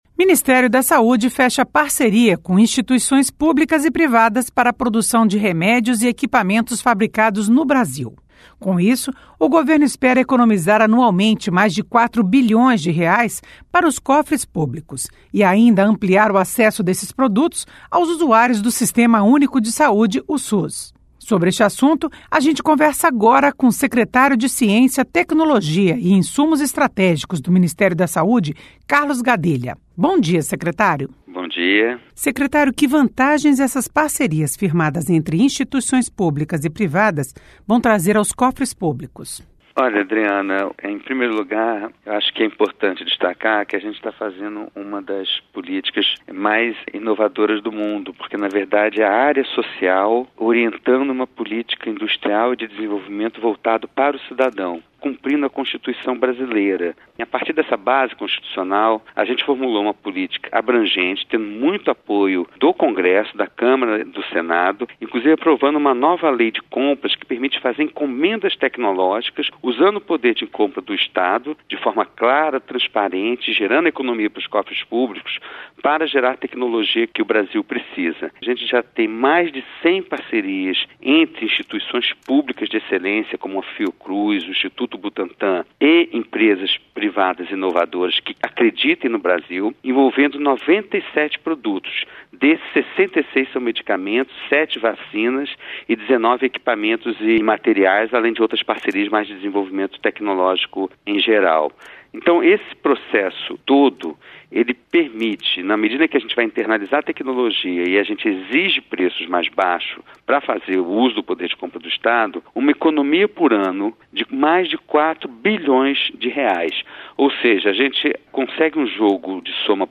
Saúde faz parceria para produzir remédios e equipamentos no Brasil Entrevista com o secretário de Ciência, Tecnologia e Insumos Estratégicos do Ministério da Saúde, Carlos Gadelha.